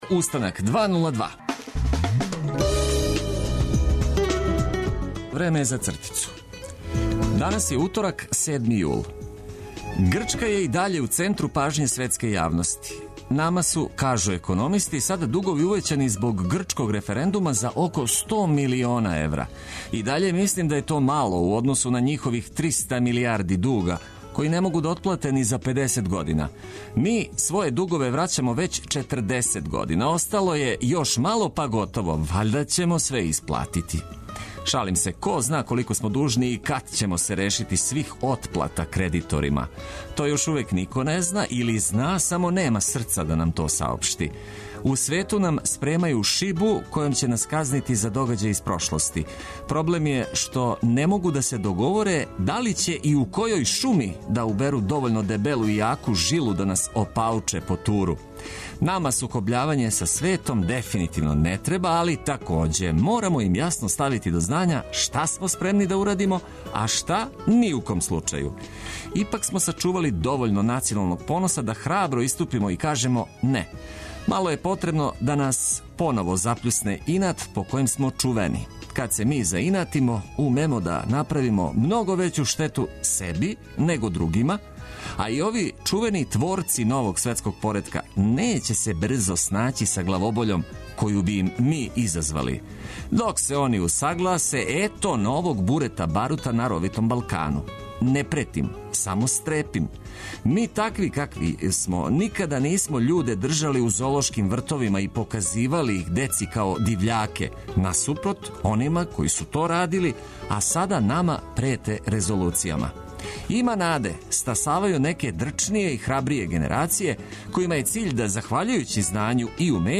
Започнимо дан уз осмех и ведру музику, добро расположени упркос свему.